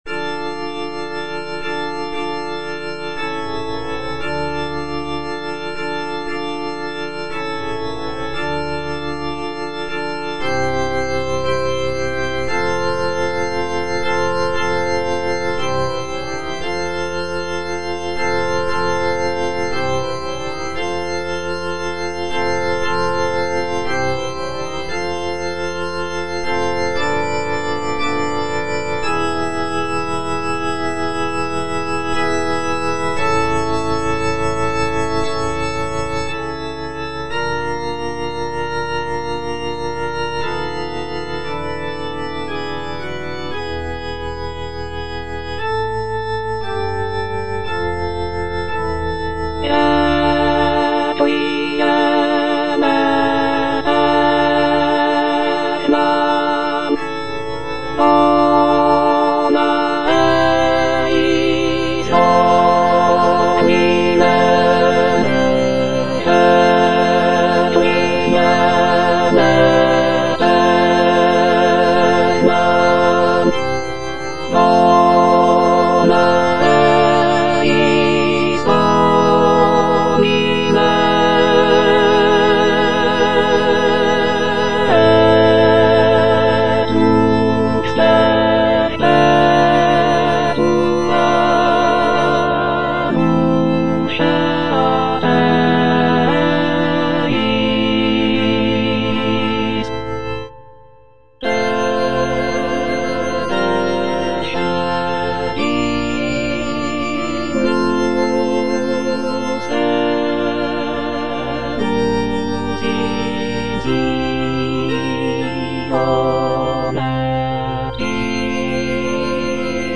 F. VON SUPPÈ - MISSA PRO DEFUNCTIS/REQUIEM Introitus (alto II) (Emphasised voice and other voices) Ads stop: auto-stop